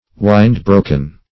Wind-broken \Wind"-bro`ken\, a.